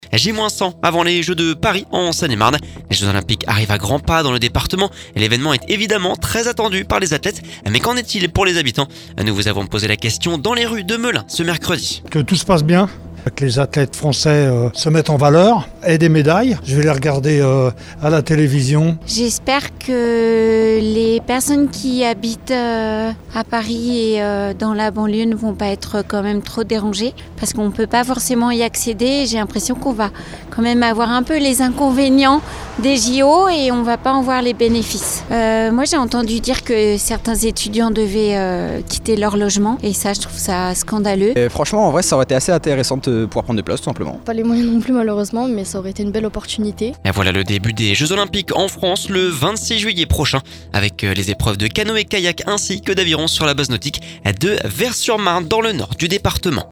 Écouter le podcast Télécharger le podcast Les Jeux Olympiques arrivent à grands pas en Seine-et-Marne, l'événement est évidemment très attendu par les athlètes mais qu’en est-il pour les pour les habitants ? Nous vous avons posé la questions dans les rues de Melun ce mercredi…